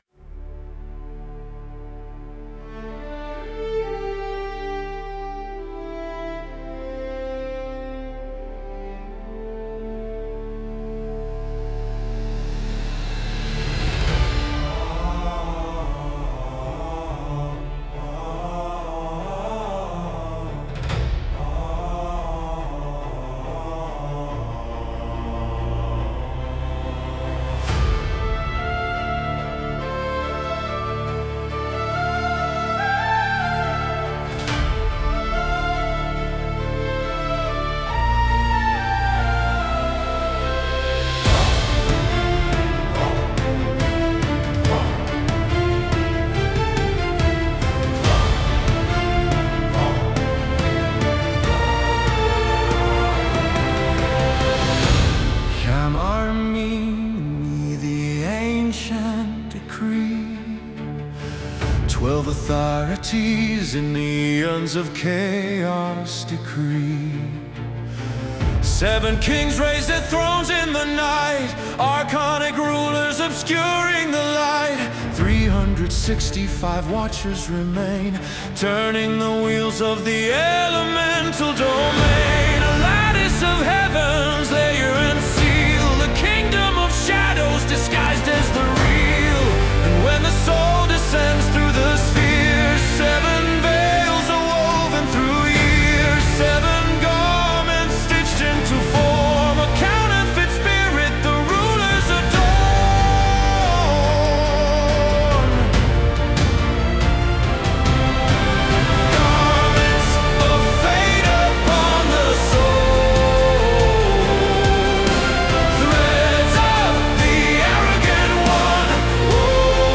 Full album with 8 Songs produced in 432Hz